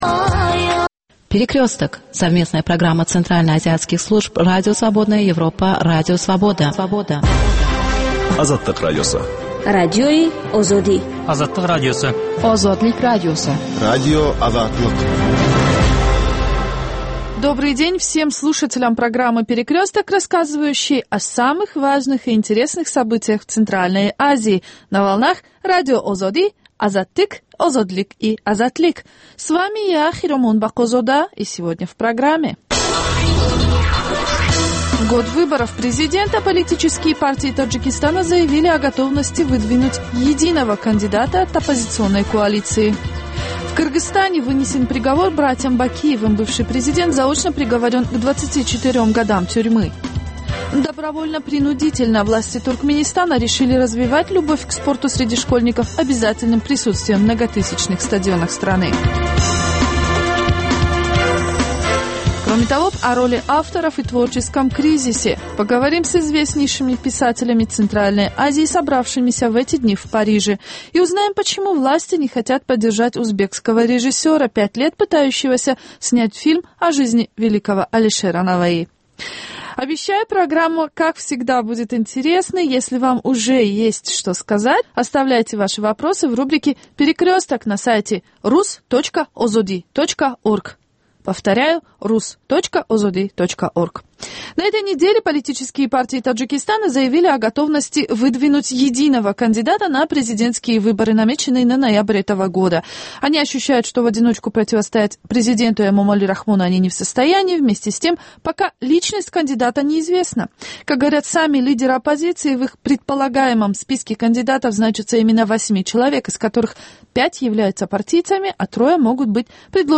Новости стран Центральной Азии. Специальная программа на русском языке.